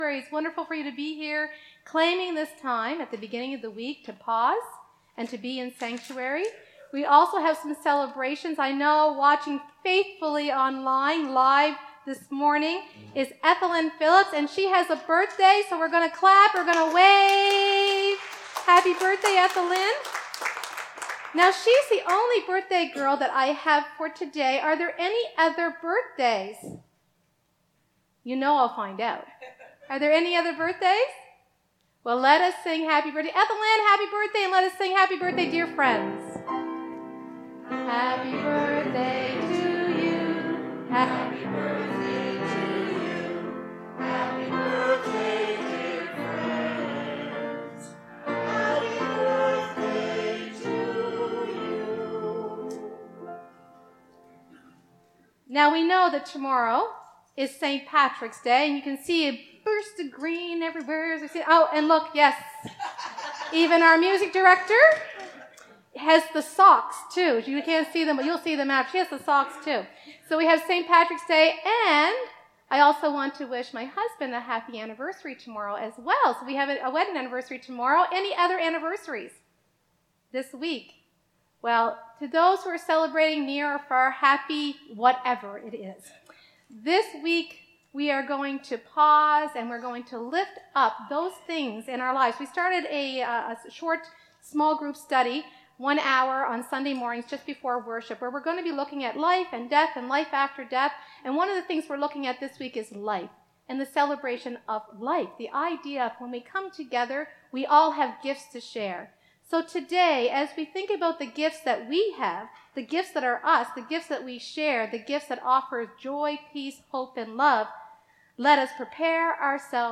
Trinity United Church Summerside Live Worship Service